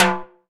9LW TIMBAL.wav